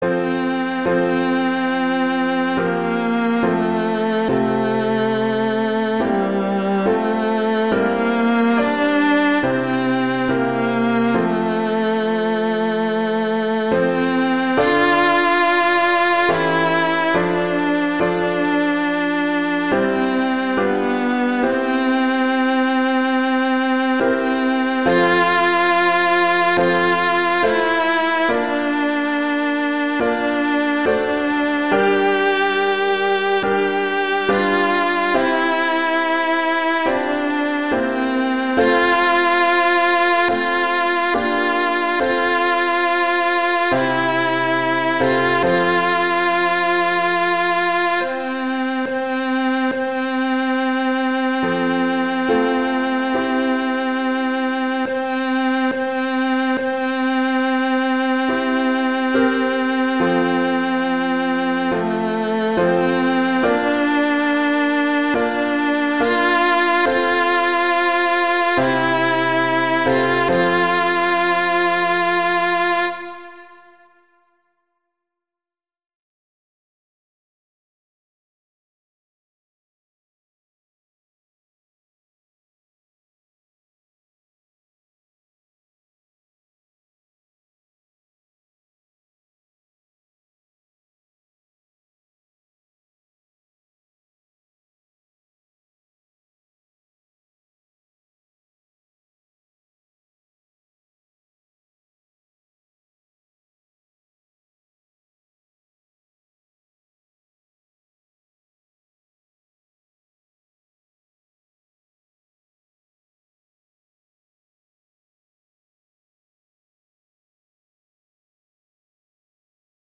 Instrumentation: viola & piano
arrangements for viola and piano
traditional, christian, holiday, hymn, sacred, children